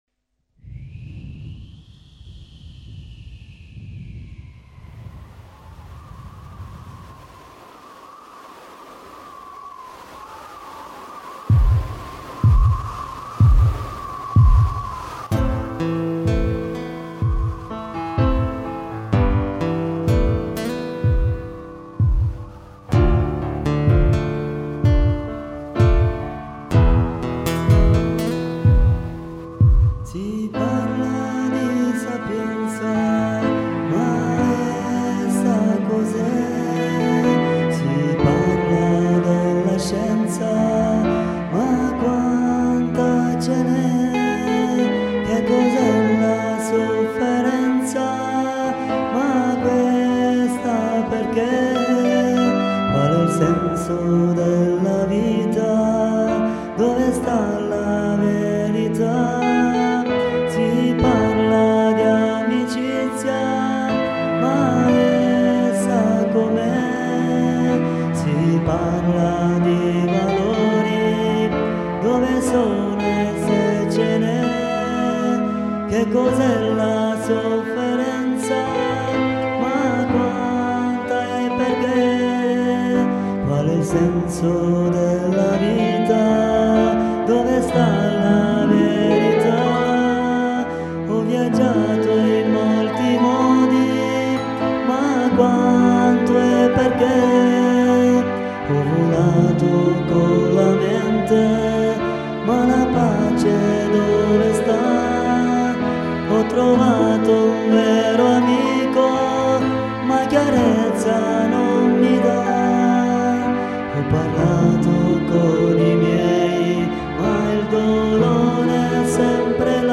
note di chitarra